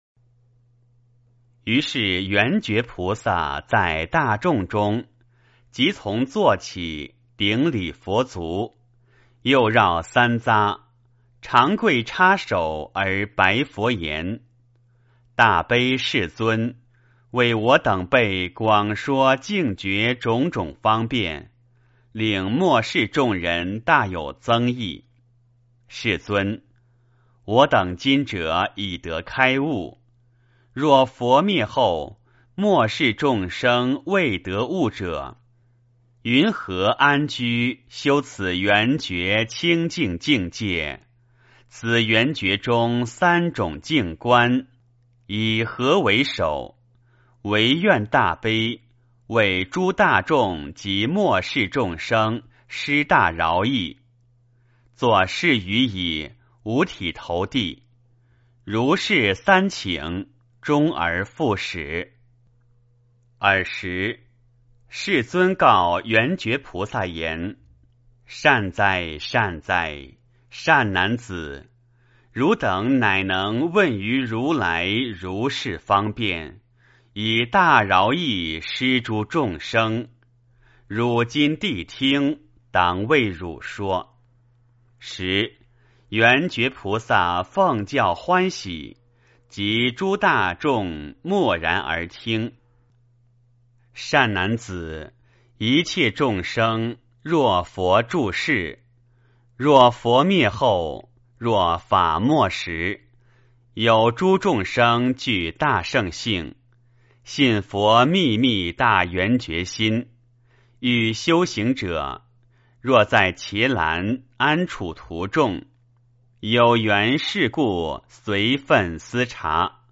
圆觉经-11圆觉菩萨 诵经 圆觉经-11圆觉菩萨--未知 点我： 标签: 佛音 诵经 佛教音乐 返回列表 上一篇： 圆觉经-09净诸业障菩萨 下一篇： 佛说八关斋经 相关文章 08治疗胃肠病咒 08治疗胃肠病咒--海涛法师...